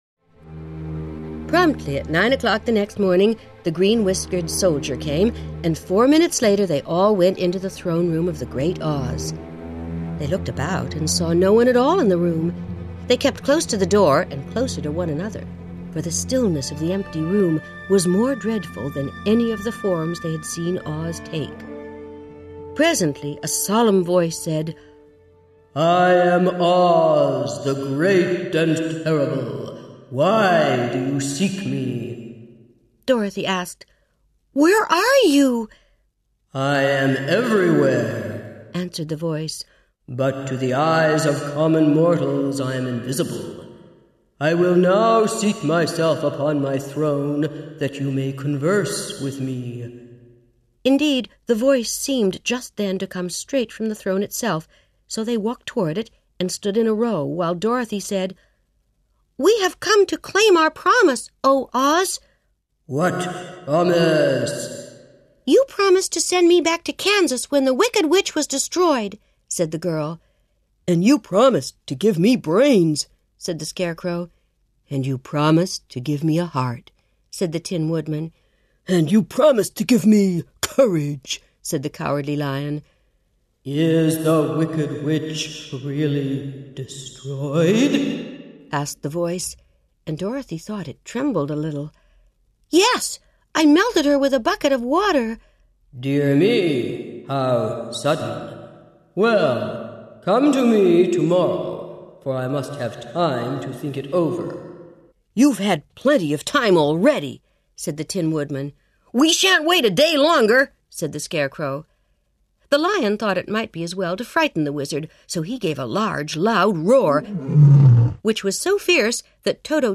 Voicereel
•  AUDIOBOOKS
21-wizard_of_oz_children_naxos.mp3